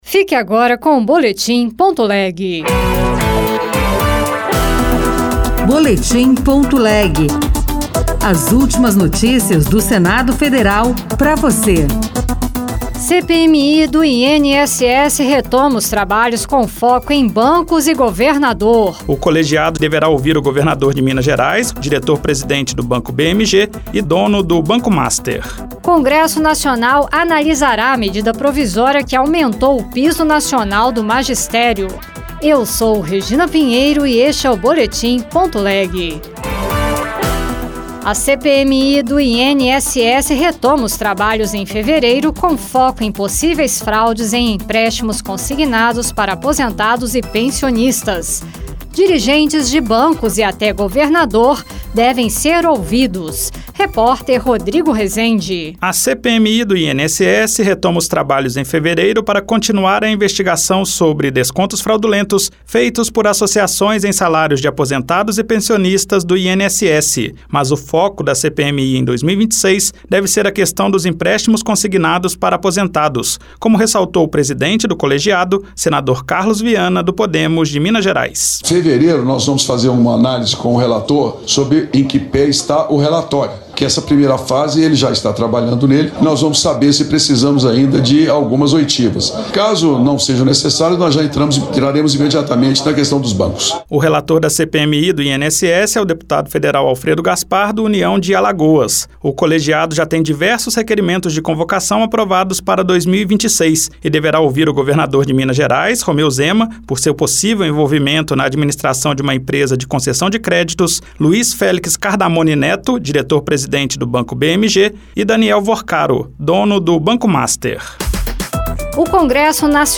RadioAgência Senado